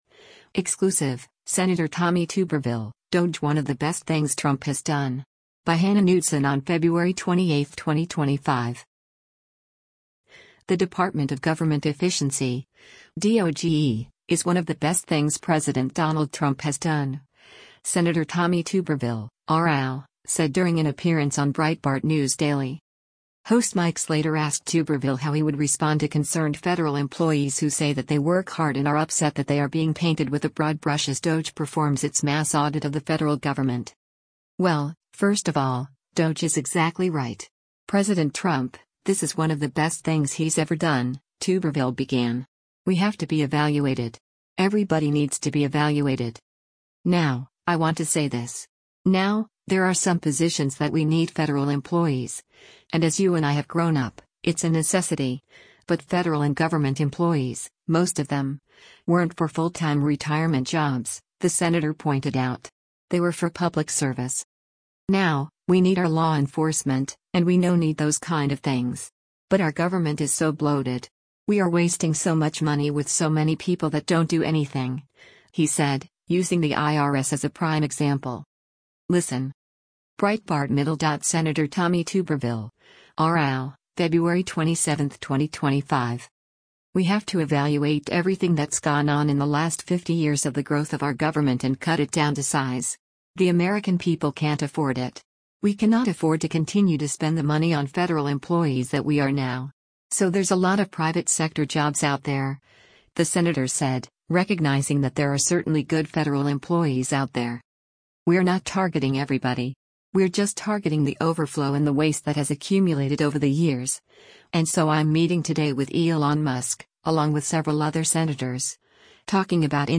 The Department of Government Efficiency (DOGE) is “one of the best things” President Donald Trump has done, Sen. Tommy Tuberville (R-AL) said during an appearance on Breitbart News Daily.